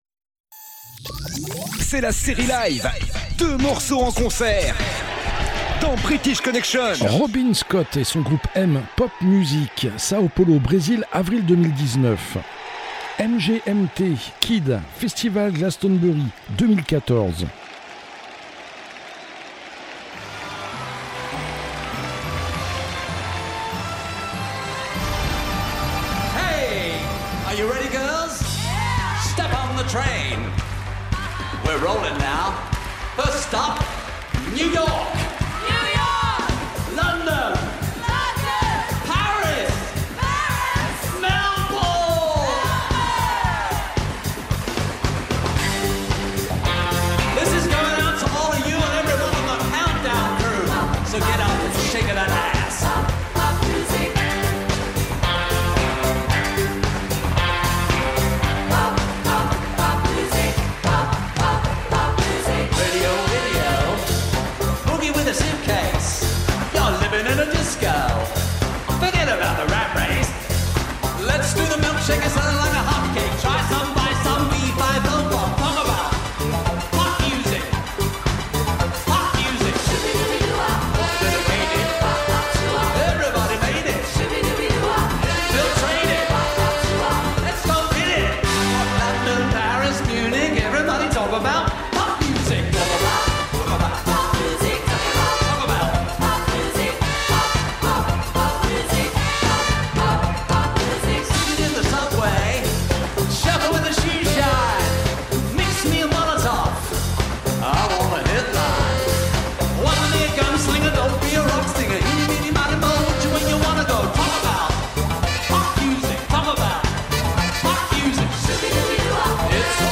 British Connection, votre émission Rock !